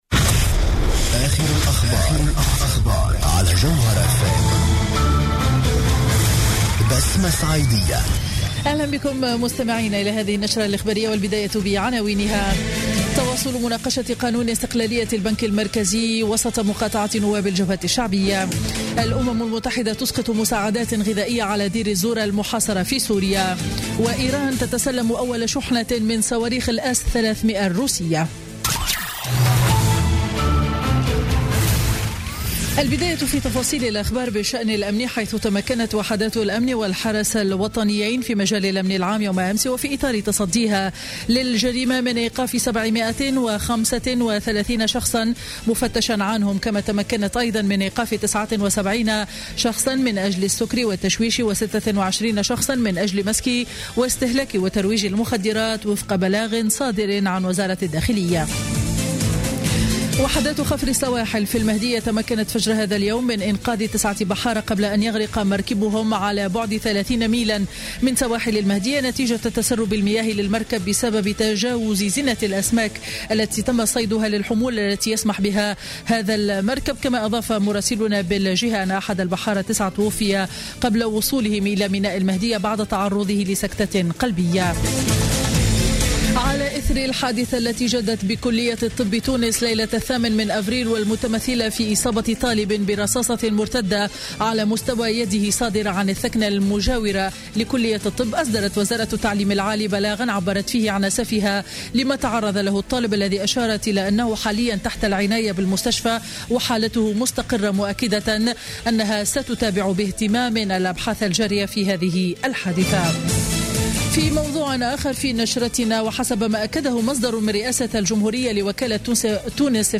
نشرة أخبار منتصف النهار ليوم الاثنين 11 أفريل 2016